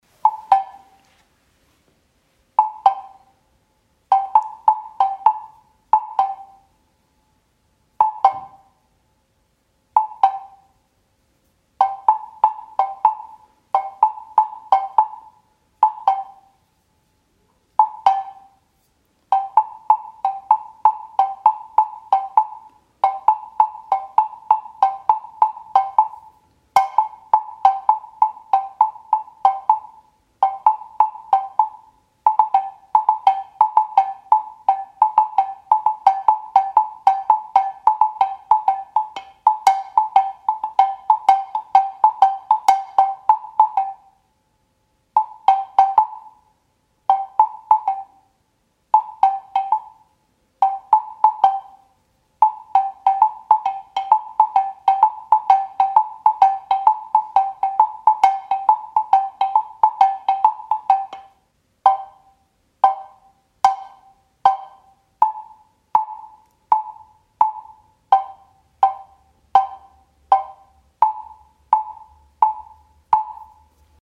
Captions English Double Bell, Brücke-Museum Berlin, 65005, composed and played by Satch Hoyt German Doppelglocke, Brücke-Museum Berlin, 65005, komponiert und gespielt von Satch Hoyt
Performance artist Satch Hoyt
Played musical instrument: Double Bell